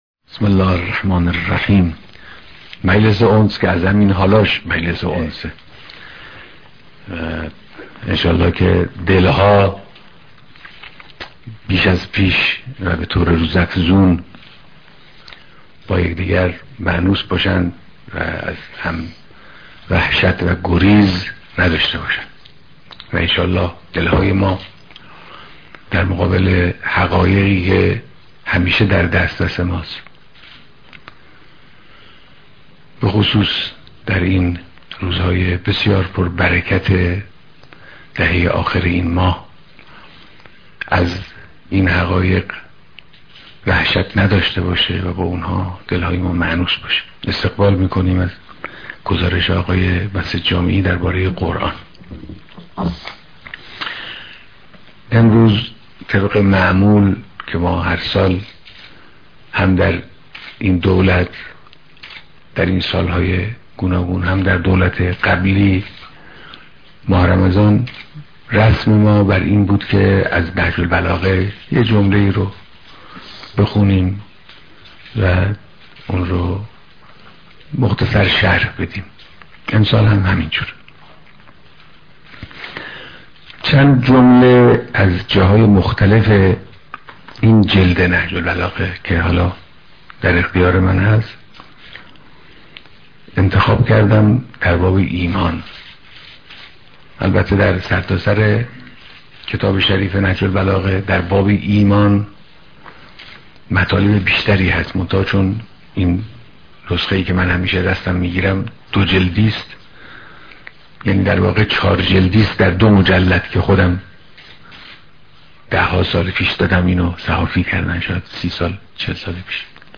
بيانات در ديدار اعضاى هيأت دولت